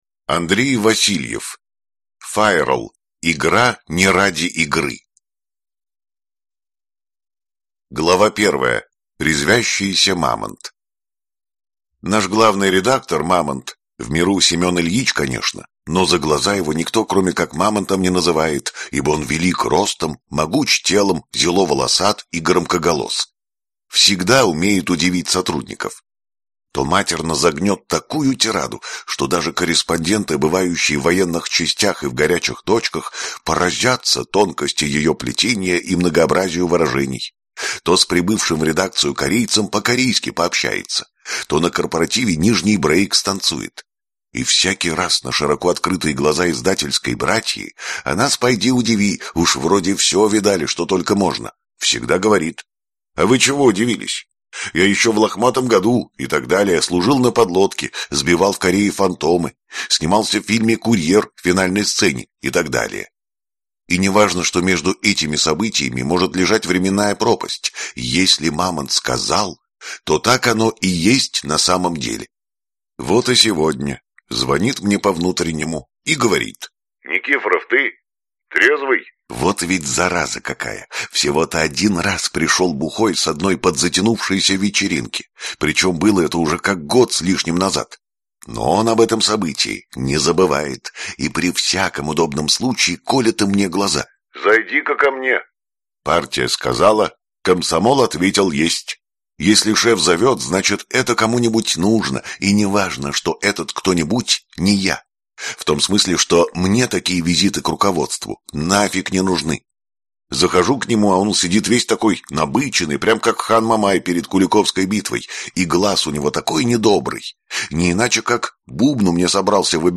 Аудиокнига Файролл. Игра не ради игры | Библиотека аудиокниг